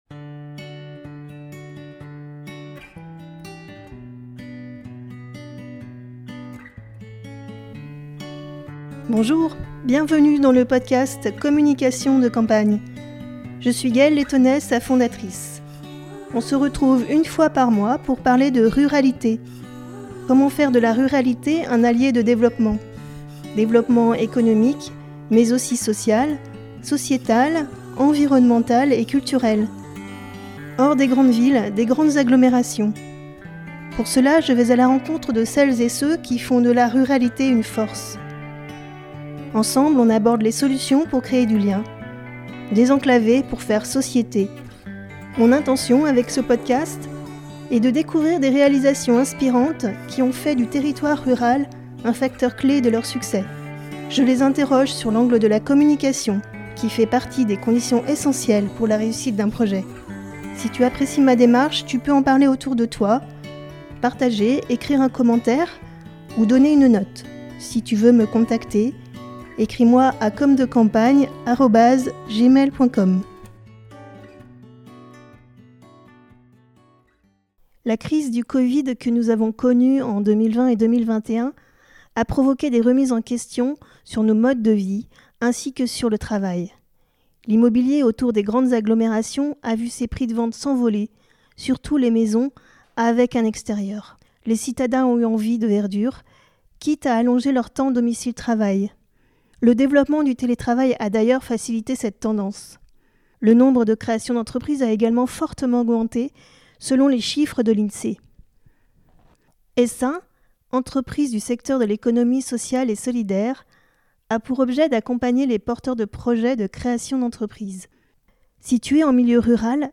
Communication de campagne Entretien